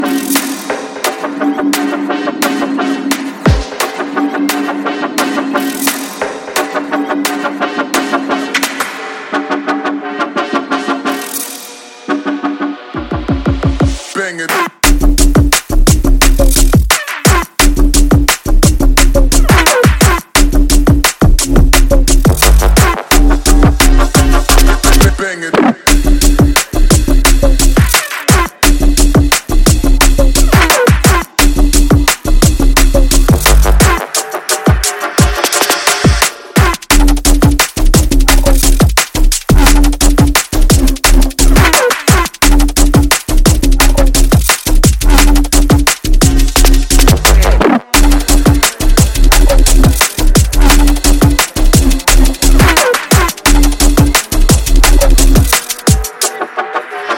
Drum&Bass